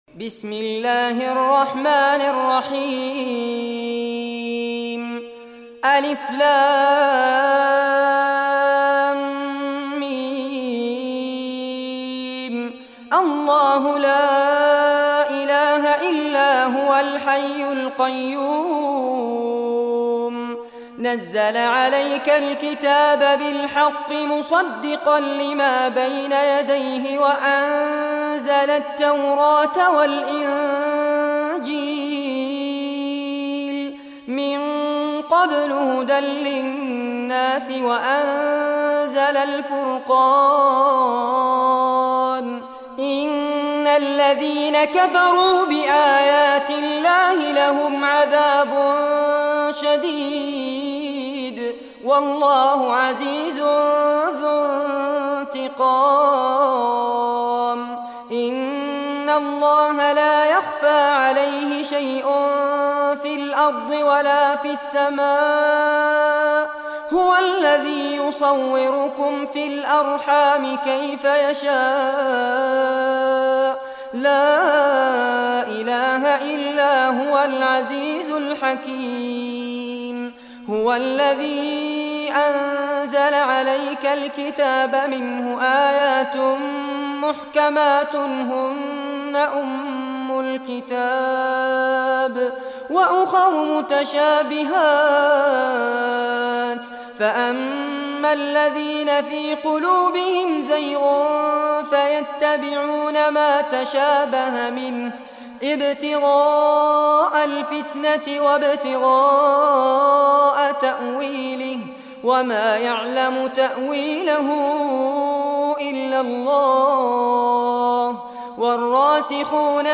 Surah Al Imran, listen or play online mp3 tilawat / recitation in Arabic in the beautiful voice of Sheikh Fares Abbad.